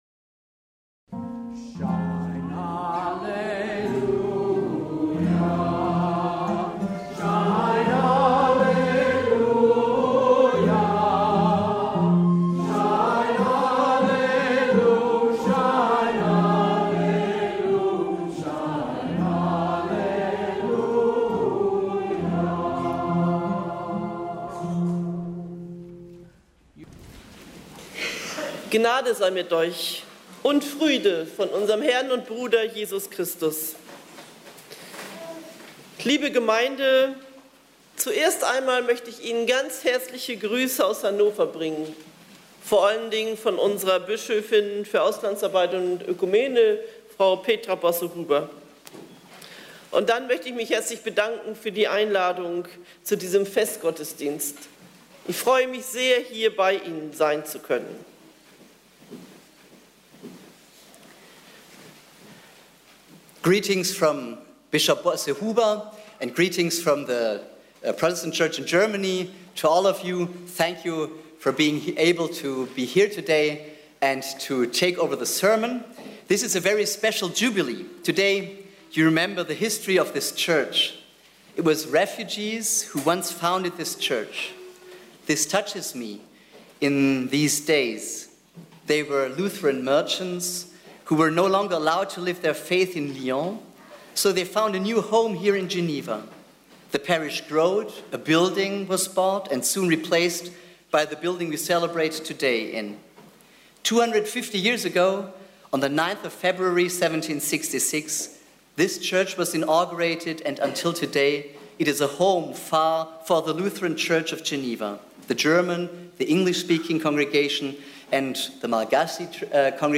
Sermon – English